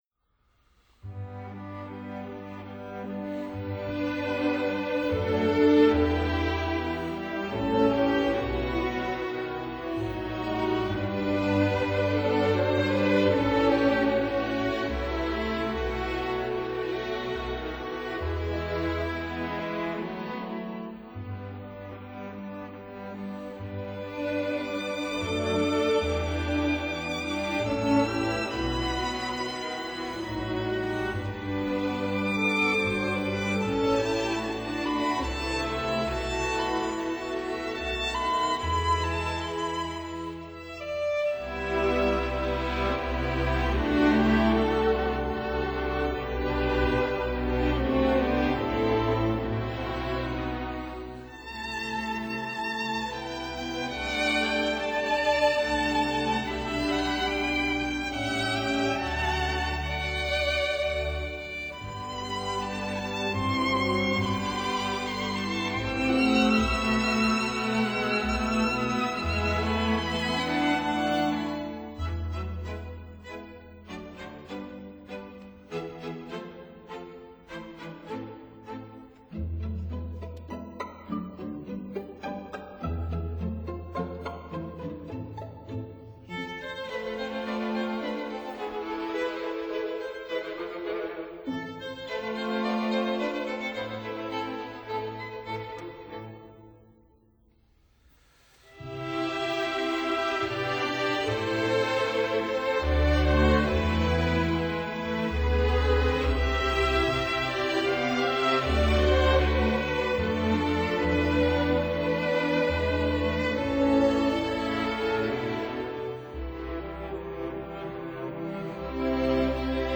•Musica pentatonica for string orchestra
violin
trumpet